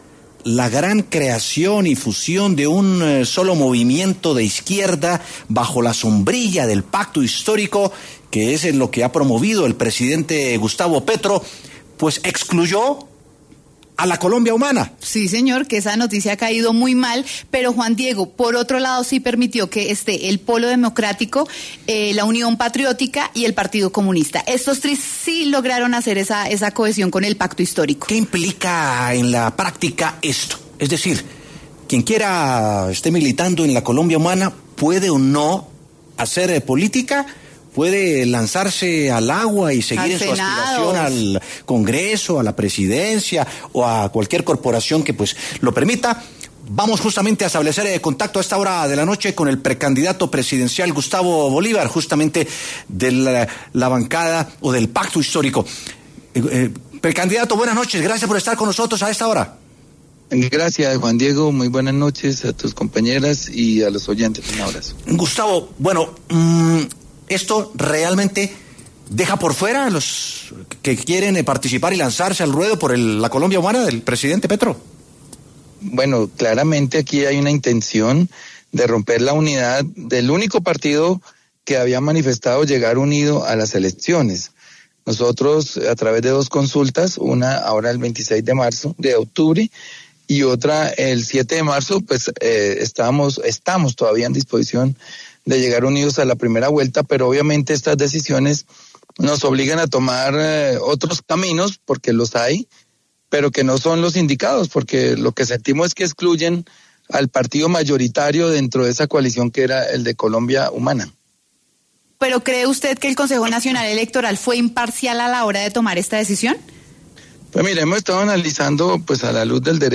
Es por esto que en los micrófonos de W Sin Carreta estuvo el precandidato presidencial Gustavo Bolívar y dio más detalles de esta decisión del CNE.